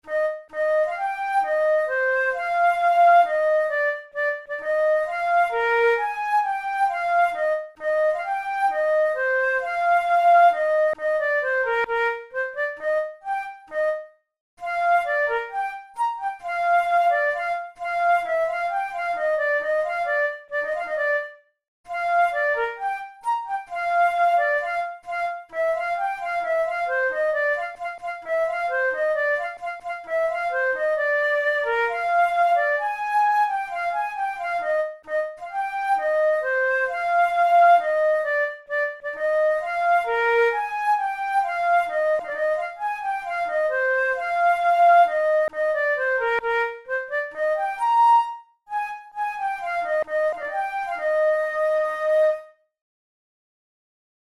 InstrumentationFlute solo
KeyE-flat major
RangeBb4–Bb5
Time signature2/4
Tempo66 BPM
Etudes, Written for Flute